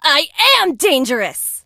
janet_kill_vo_01.ogg